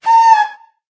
affectionate_scream.ogg